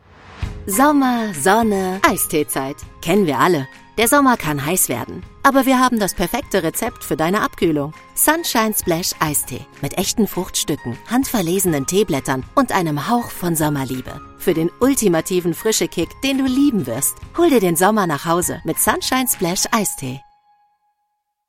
Sprecherin, Schauspielerin